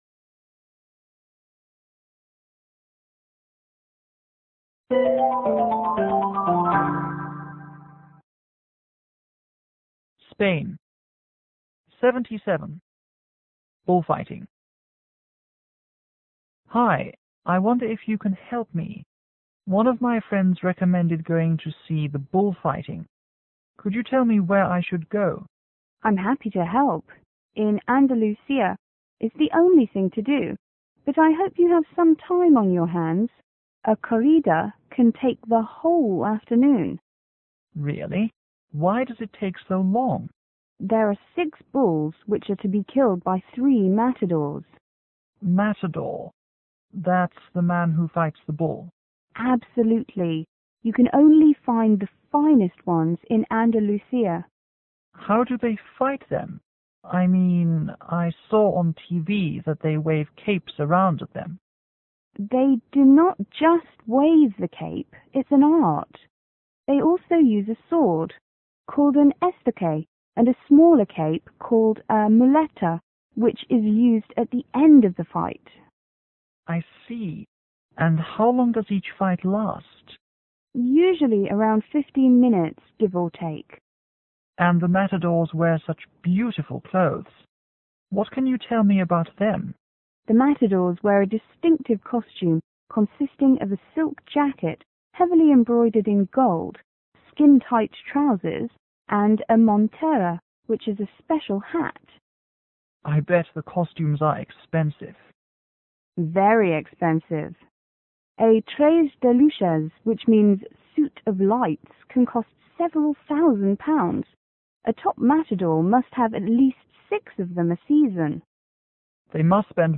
L: Local     T：Tourist